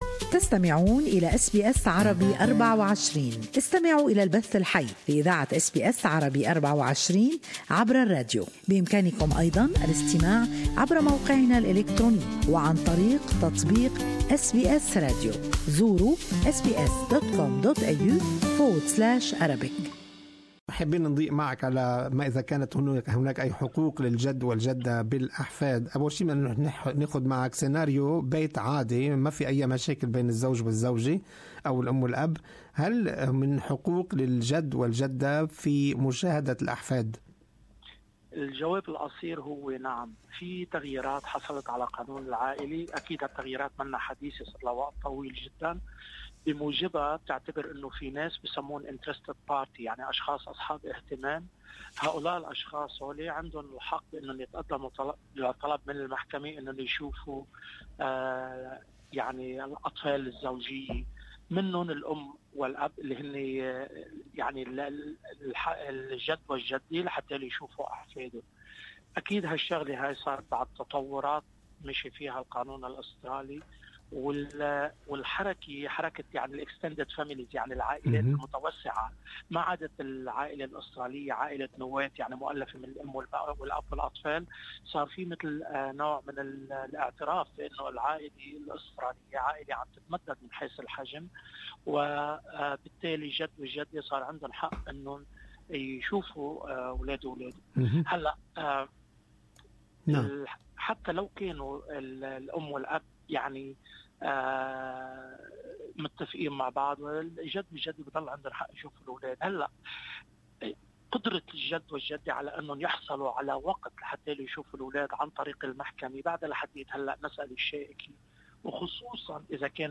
المزيد عن حقوق الجد والجدة في هذا التقرير